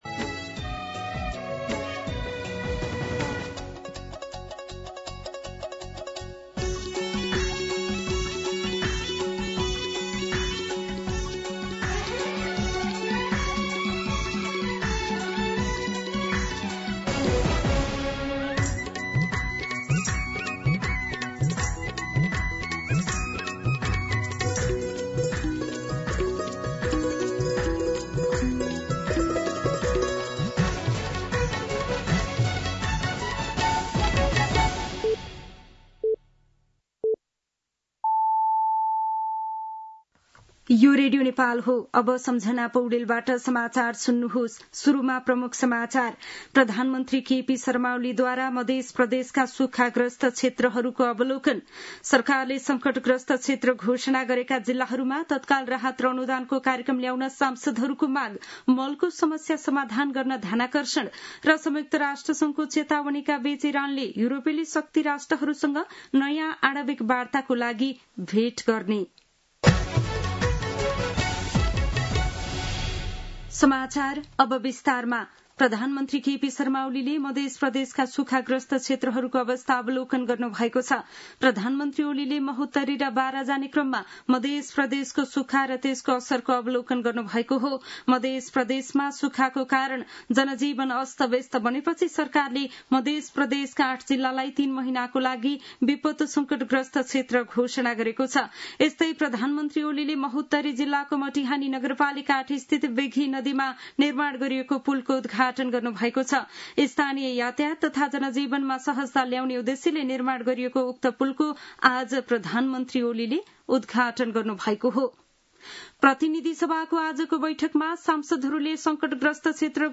दिउँसो ३ बजेको नेपाली समाचार : ९ साउन , २०८२